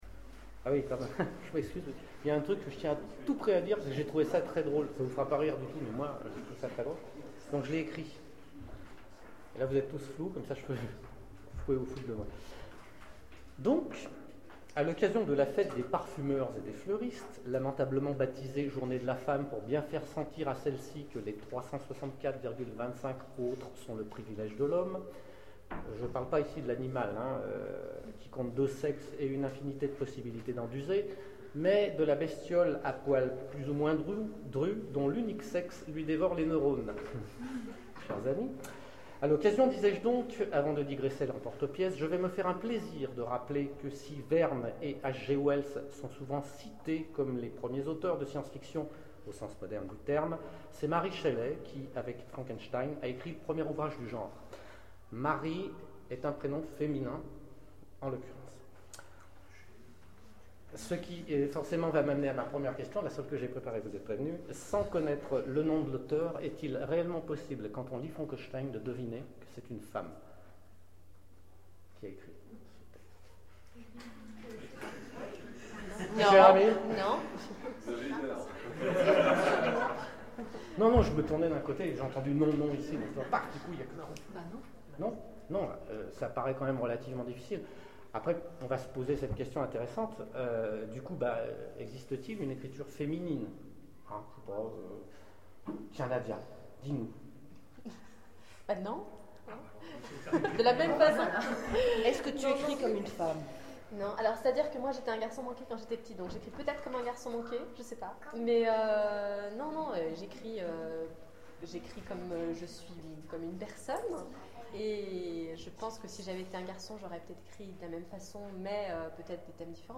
Oniriques 2013 : Conférence Pour en finir avec la fiction féminine